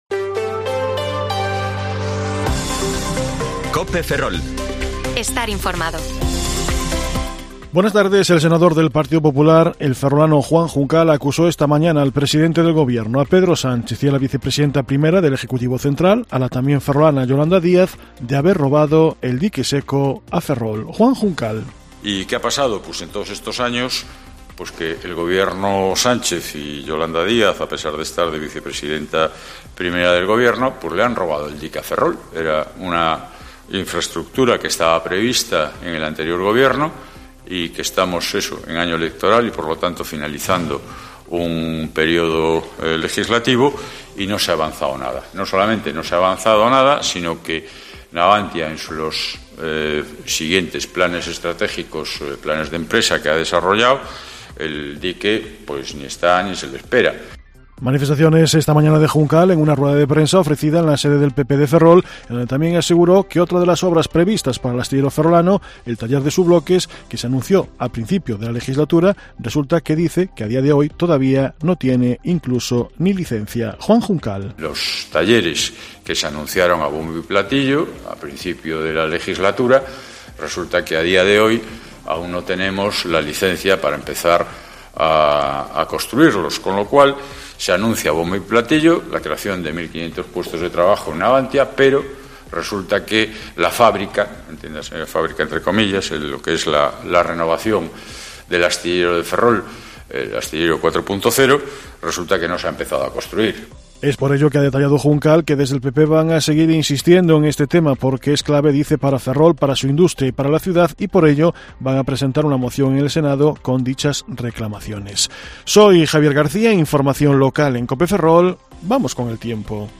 Informativo Mediodía COPE Ferrol 23/3/2023 (De 14,20 a 14,30 horas)